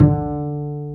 Index of /90_sSampleCDs/Roland - String Master Series/STR_Cb Pizzicato/STR_Cb Pizz 2